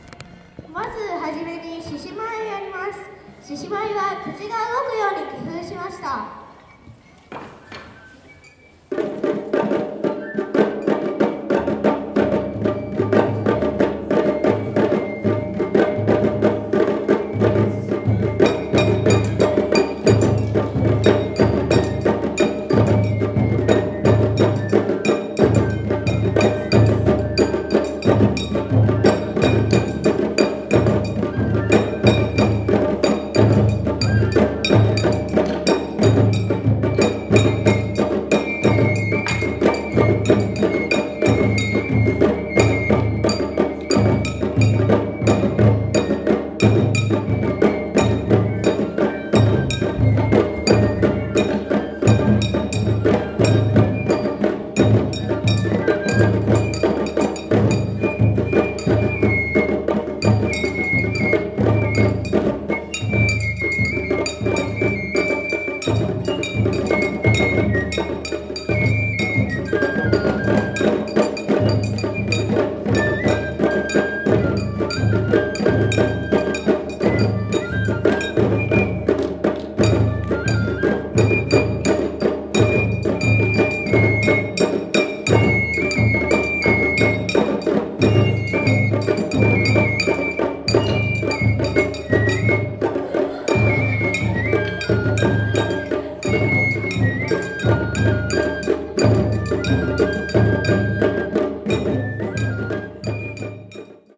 獅子踊りからです。